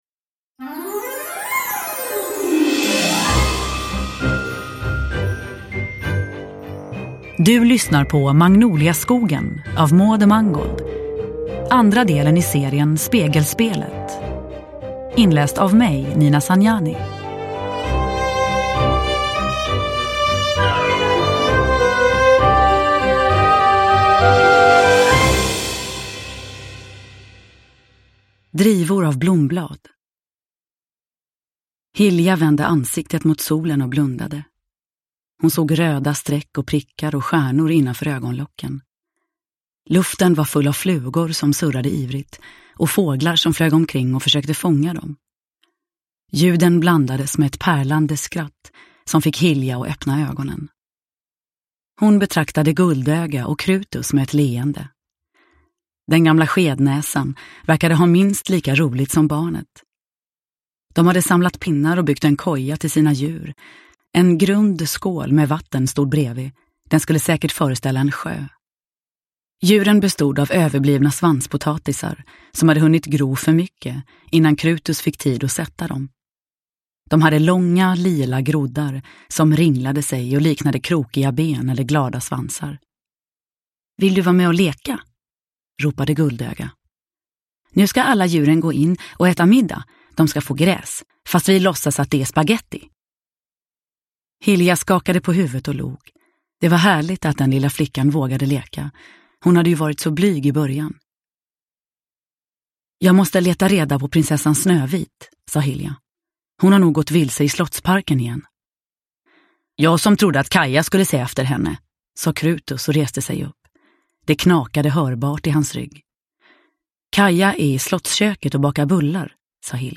Magnoliaskogen – Ljudbok – Laddas ner
Uppläsare: Nina Zanjani